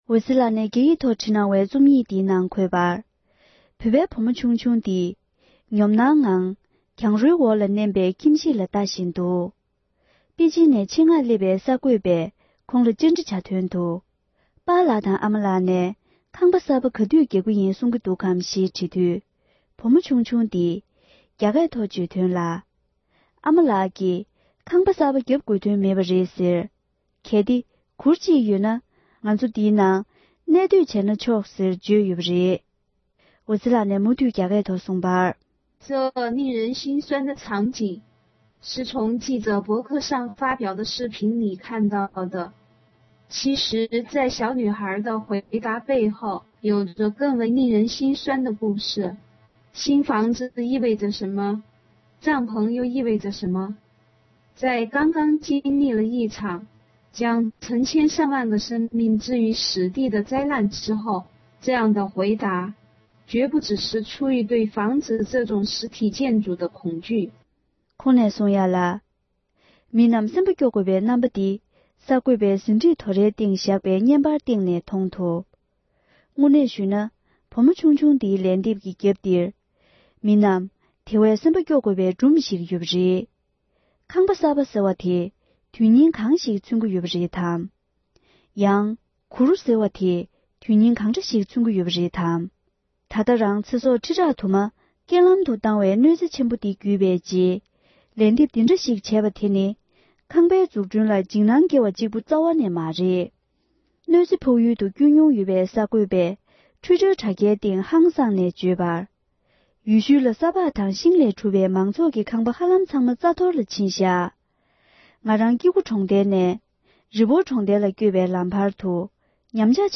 བོད་སྐད་ཐོག་ཕབ་བསྒྱུར་གྱིས་སྙན་སྒྲོན་ཞུས་པར་གསན་རོགས